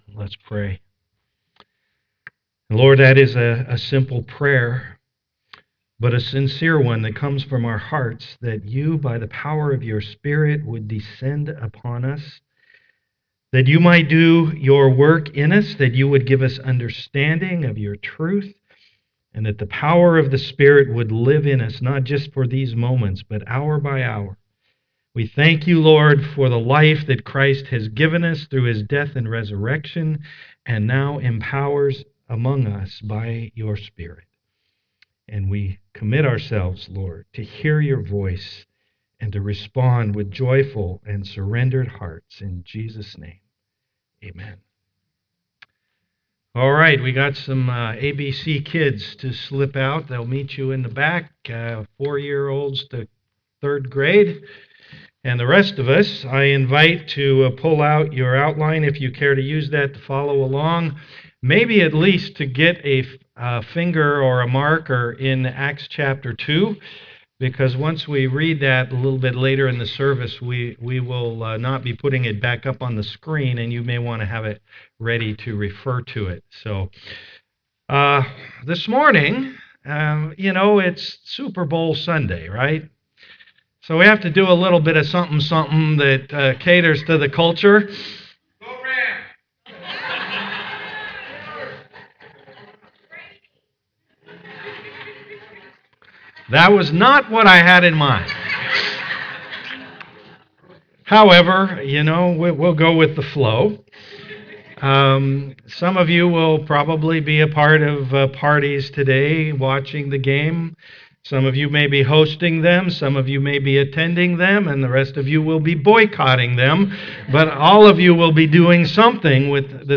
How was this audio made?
The audio of this message includes the audio from a video clip shown. We showed the first 4+ minutes.